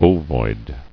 [o·void]